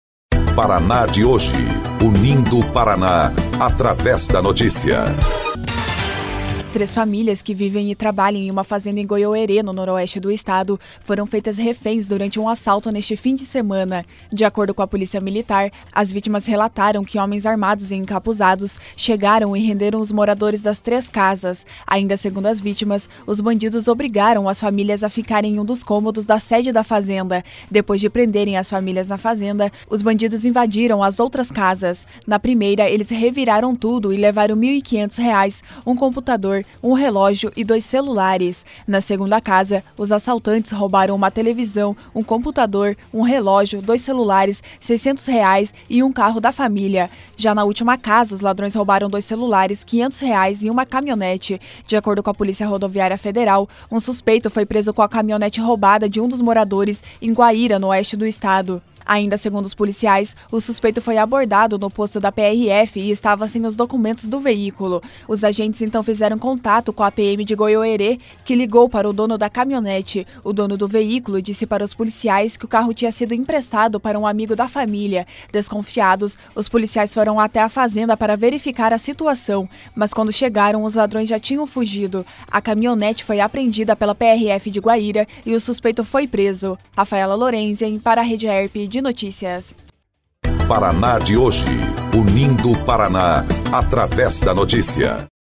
23.04 – BOLETIM – Três famílias são feitas reféns durante assalto em Goioerê